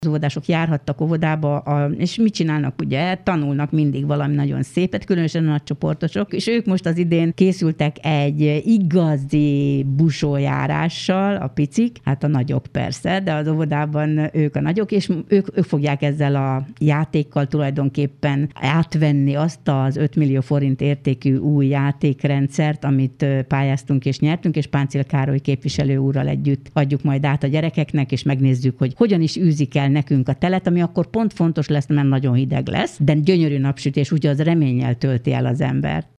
A Magyar Falu program keretében 4,9 millió forintot nyert erre az önkormányzat. Dr. Kendéné Toma Mária polgármester volt rádiónk vendége.